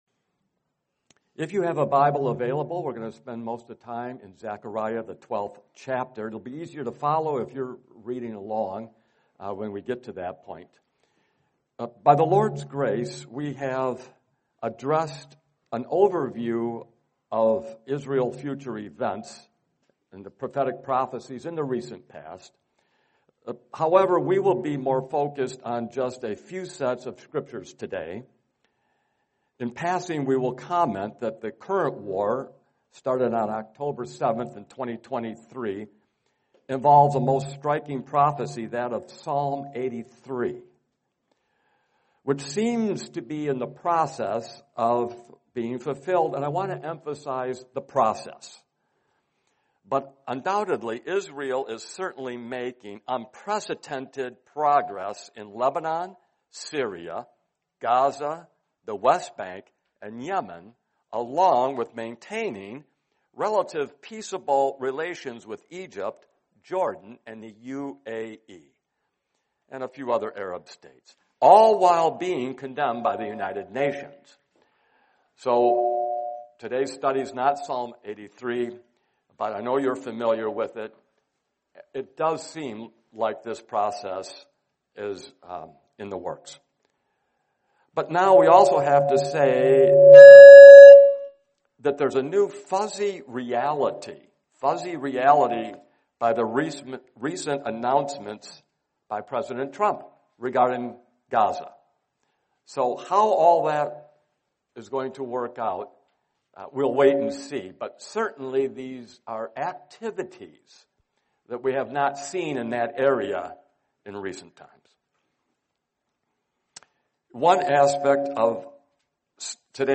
Series: 2025 Wilmington Convention